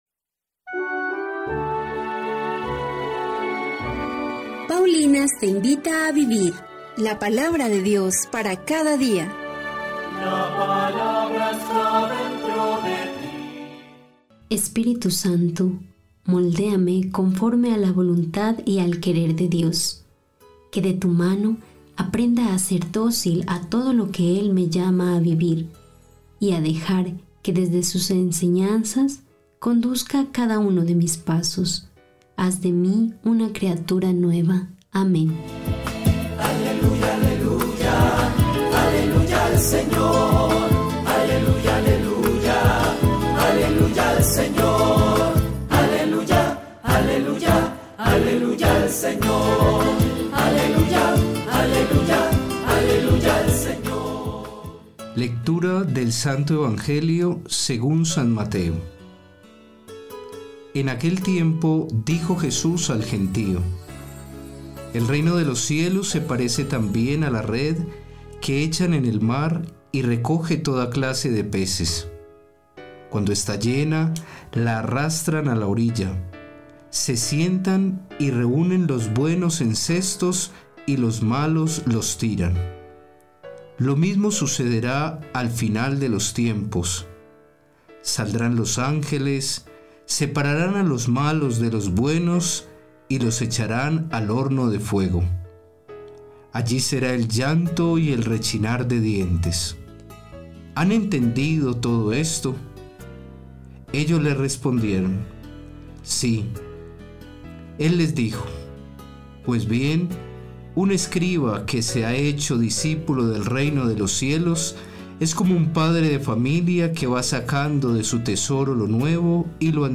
Lectura del libro del Éxodo 33, 7-11; 34, 5b-9. 28